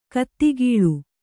♪ kaattigīḷu